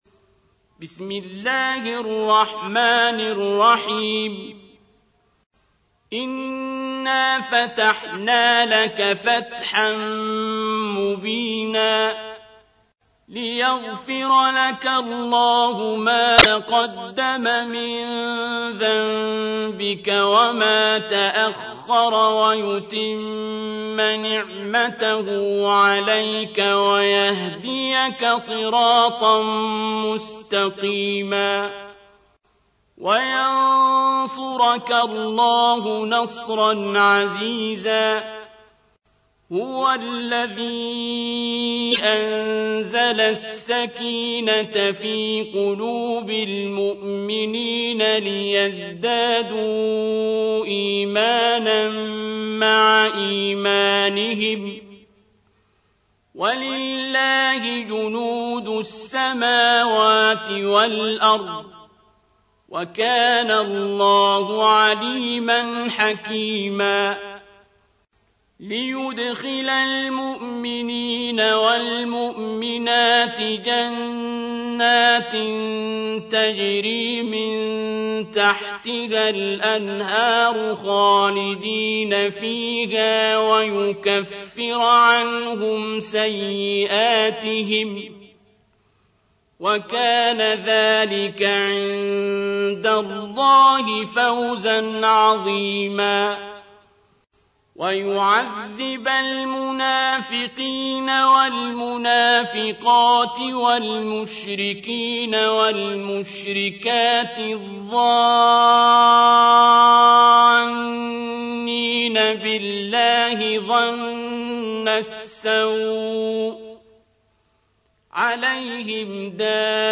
تحميل : 48. سورة الفتح / القارئ عبد الباسط عبد الصمد / القرآن الكريم / موقع يا حسين